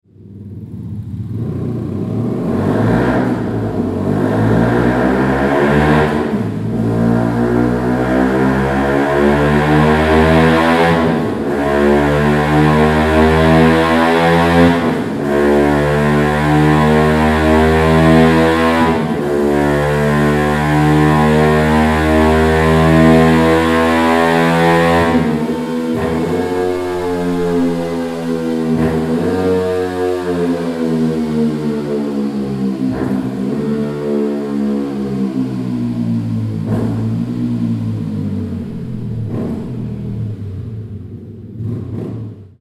• Ligne avec silencieux finition Carbone